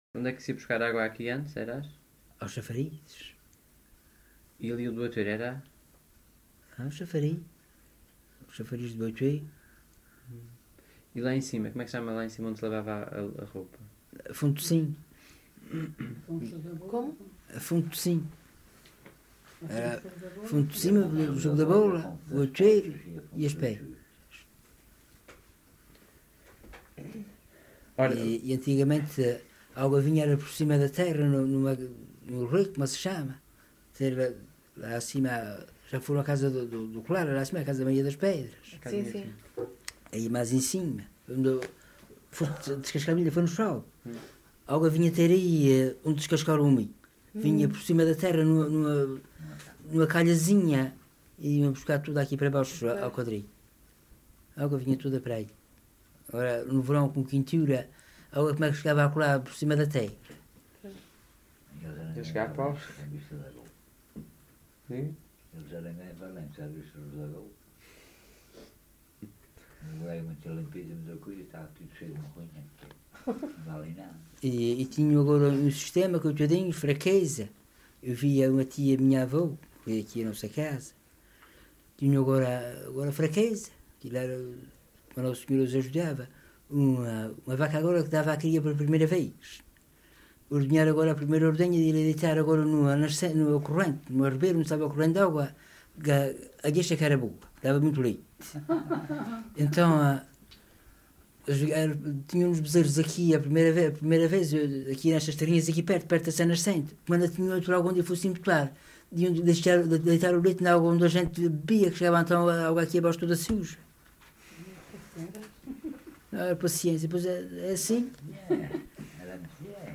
LocalidadeVila do Corvo (Corvo, Horta)